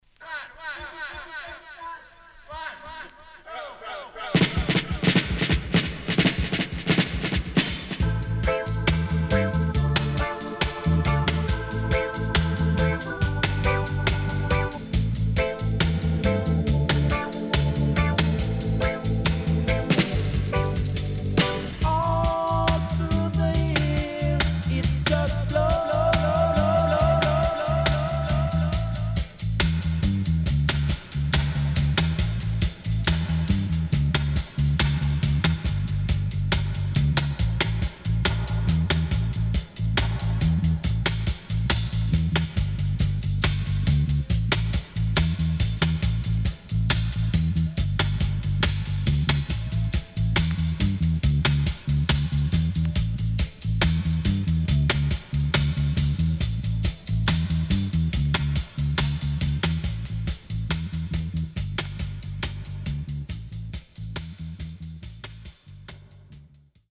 SIDE A:少しプチノイズ入ります。